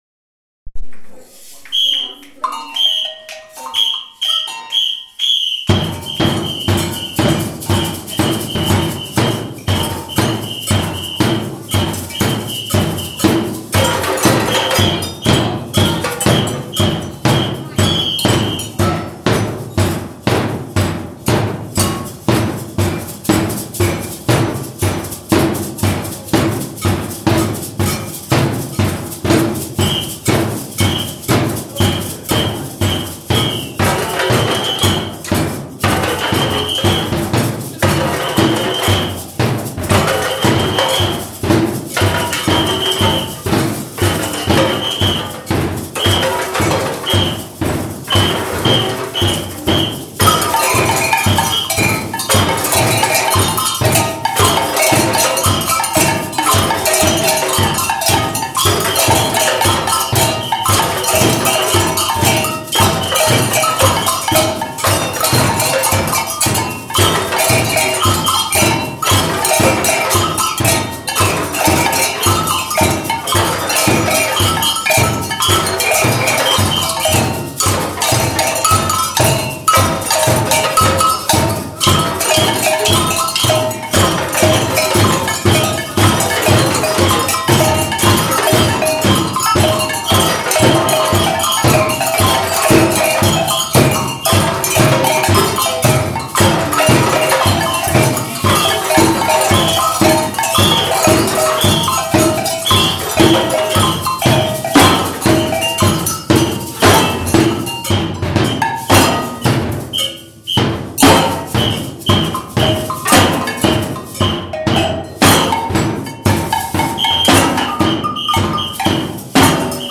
8LC SAMBA BAND